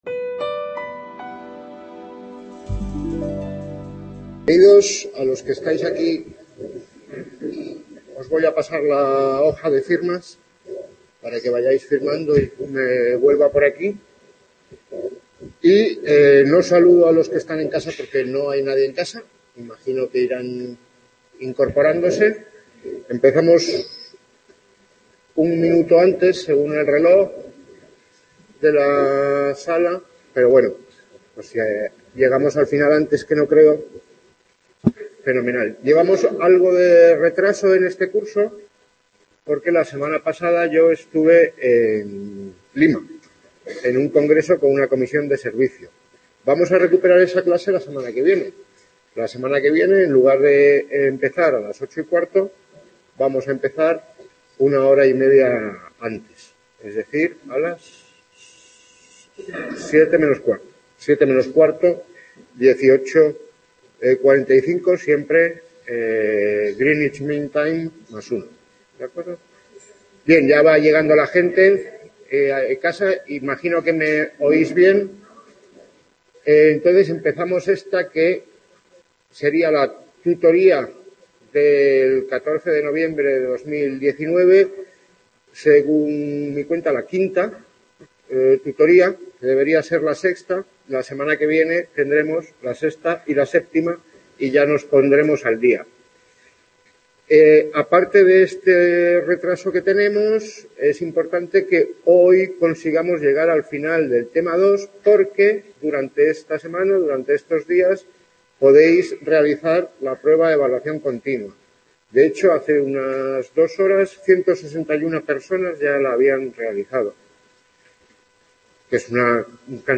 Quinta tutoría en el C A. de Madrid-Gregorio Marañón Tema 2.4. El léxico